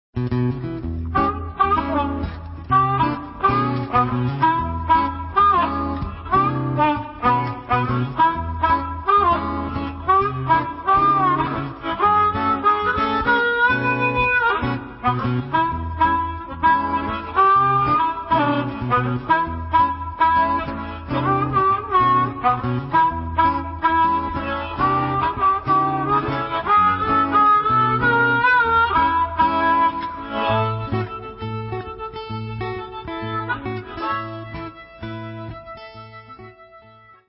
Two instrumental suites.